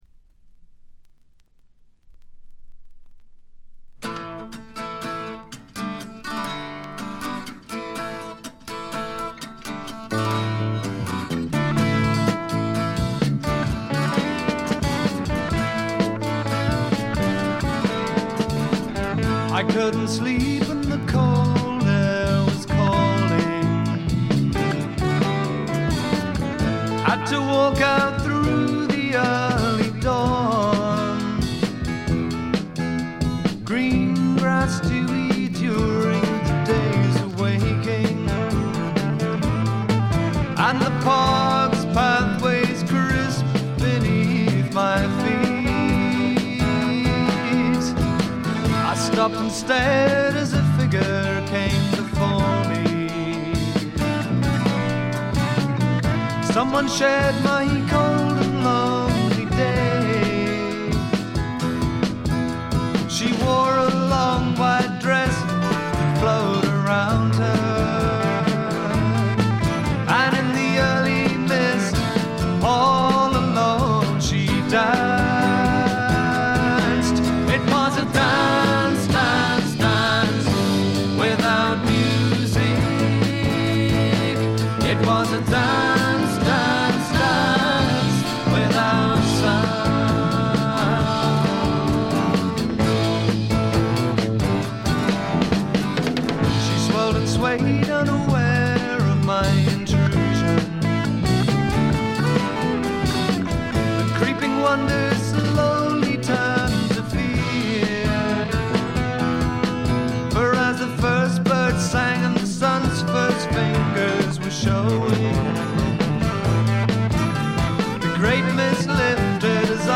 わずかなノイズ感のみ。
生きのよいフォークロックが最高ですよ。
試聴曲は現品からの取り込み音源です。
Electric Bass, Double Bass, Tenor Banjo
Drums, Bongos